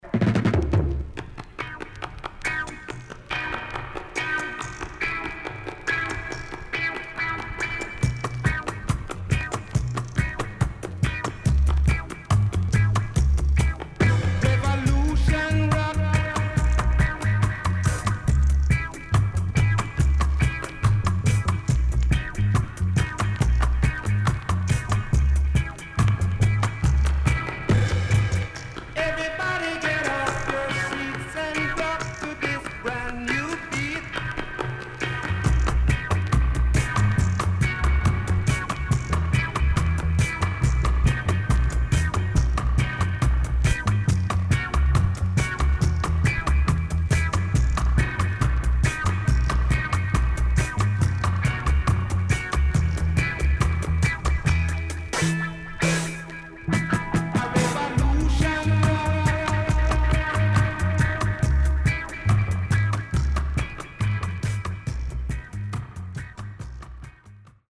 HOME > REGGAE / ROOTS
CONDITION SIDE A:VG(OK)
riddim
SIDE A:所々チリノイズがあり、少しプチノイズ入ります。
少し針ブレあり。